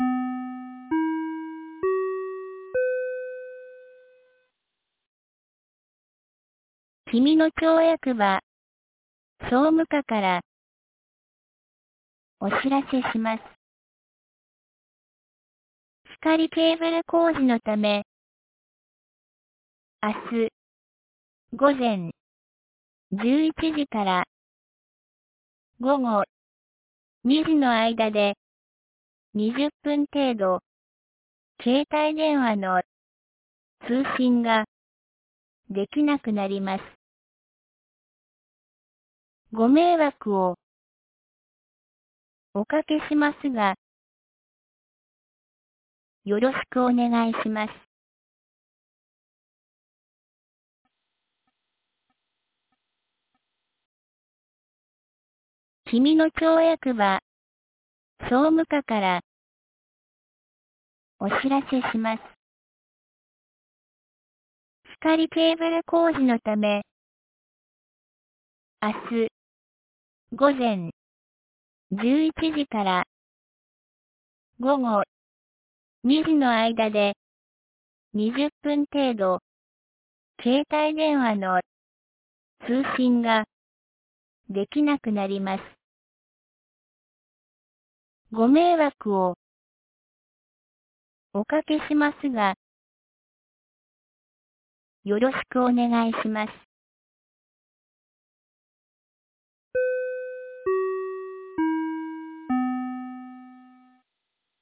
2022年05月25日 17時16分に、紀美野町より上神野地区へ放送がありました。
放送音声